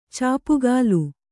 ♪ cāpugālu